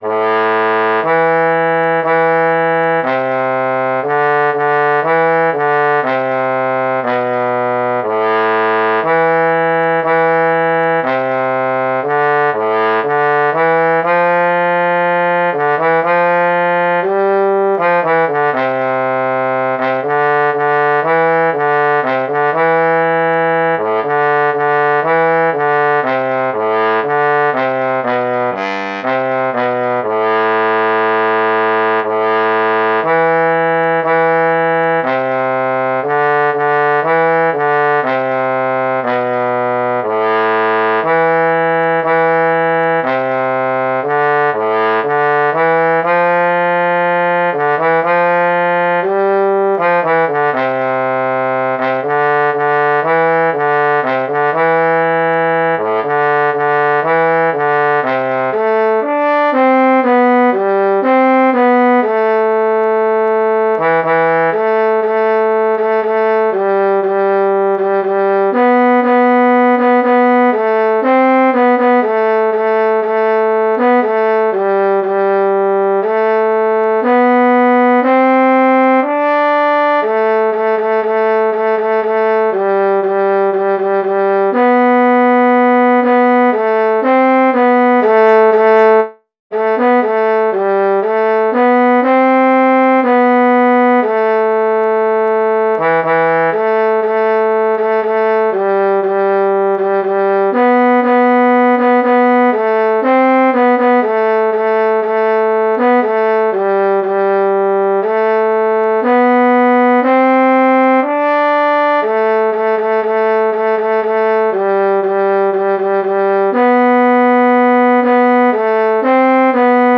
Jewish Folk Song